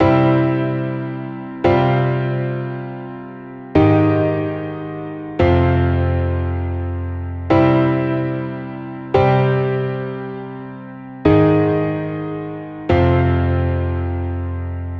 По сути, там тональность C, но при этом присутствуют ноты D# и G# и все при этом звучит гармонично. Получается при игре задействованы ноты С, D#, E, F, G, G#, что не укладывается в ноты тональности С (да и вообще ни в одну натуральную тональность)...